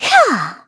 Xerah-Vox_Attack2_kr_Madness.wav